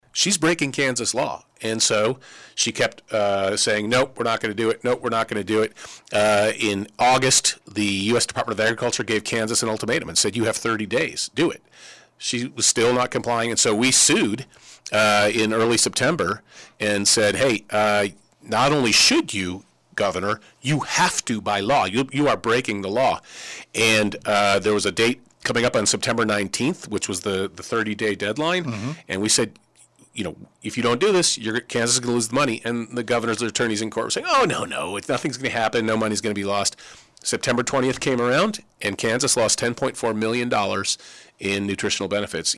Kobach tells KSAL News, “To me it just looks like political theater, just showing that she is part of the resistance to the Trump administration.”